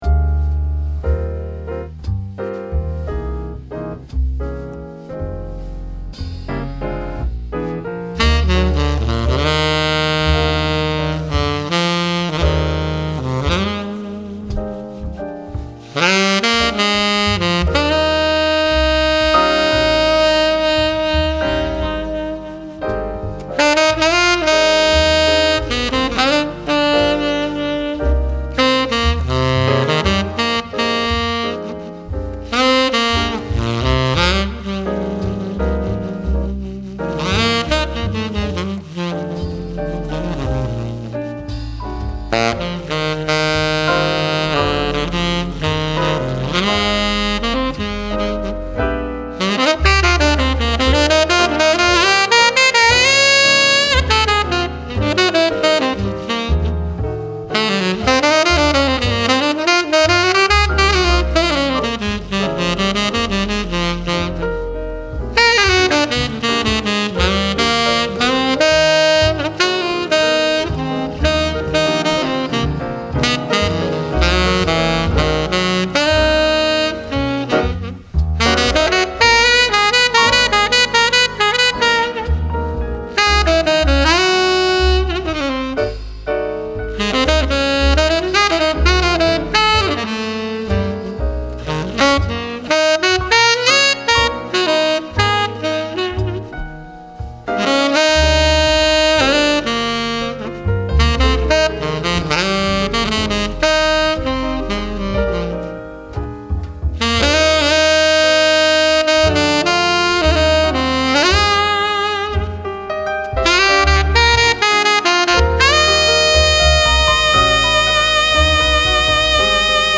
精巧なマシニングとこだわりの手作業から生み出されるのは、厚みがあり、渋く雑味のあるジャズ本来のサウンド。
それが今回、さらに深く響き渡る「極上のダークサウンド」へと劇的な進化を遂げました。
種類: テナー
音色と特徴：ダーク
スタイル：ジャズ